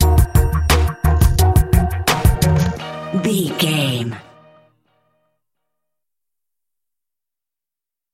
Uplifting
Aeolian/Minor
E♭
drum machine
synthesiser
percussion